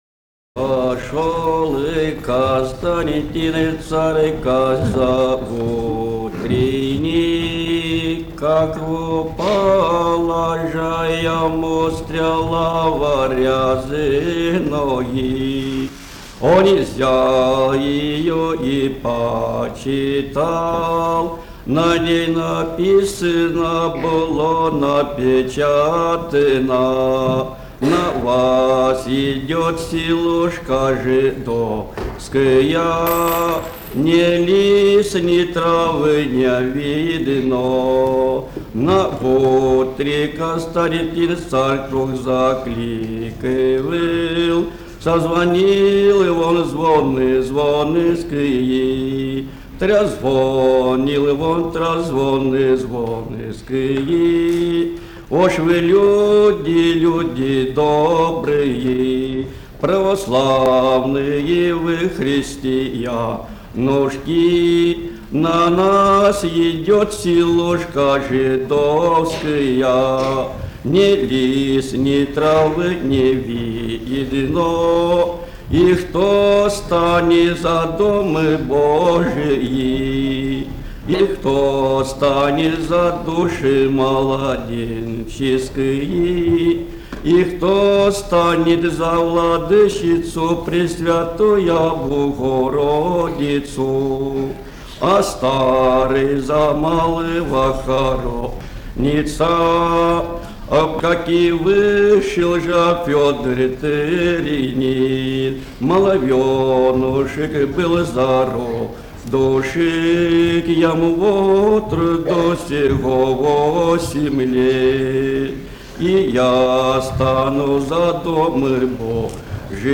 Собрание имеет звуковое приложение – диски с записью аутентичного исполнения былинных песен и духовных стихов самими носителями эпических традиций.